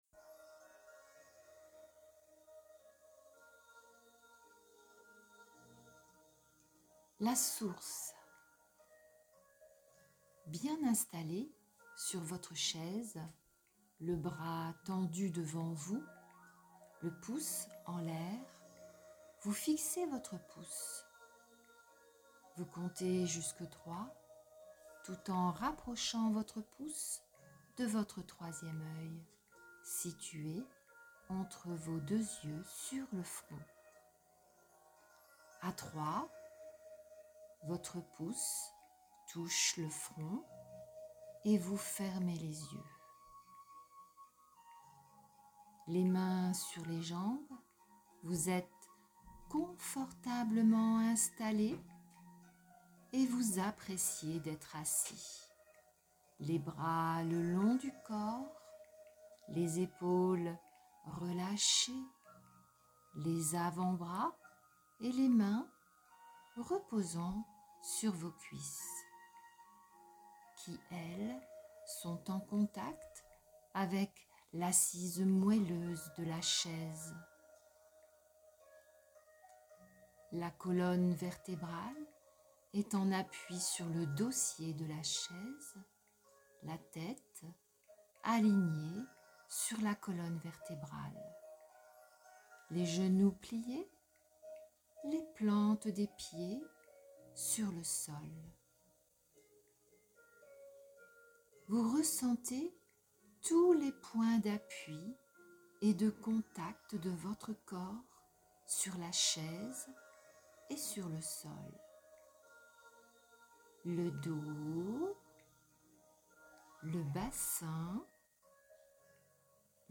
Voici pour commencer, un fichier son à écouter ou à télécharger gratuitement, pour vous accompagner à une Ballade sonore vers la Source.
la-source-paroles-musique.mp3